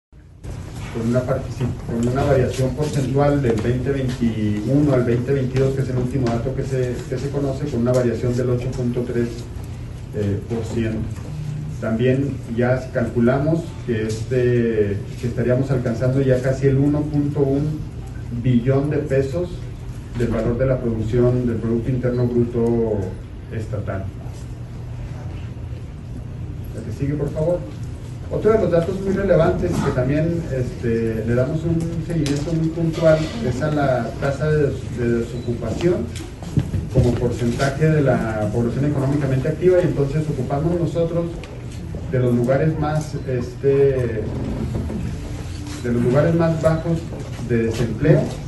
AUDIO: JOSÉ DE JESÚS GRANILLO, TITULAR DE LA SECRETARÍA DE HACIENDA DEL ESTADO
Chihuahua, Chih.- El secretario de Hacienda estatal, José de Jesús Granillo, compareció ante el pleno del Congreso del Estado de Chihuahua, en donde abordó pormenores del Paquete Fiscal 2024.